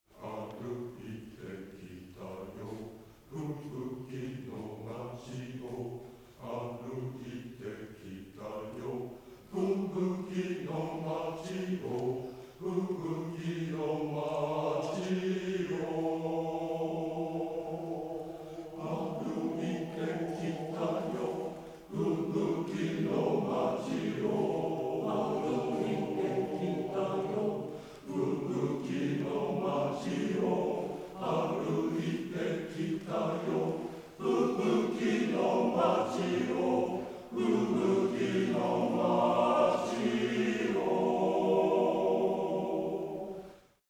平成24年2012年11月15日　ヴィサン　ジョイントコンサート  横浜みなとみらい大ホール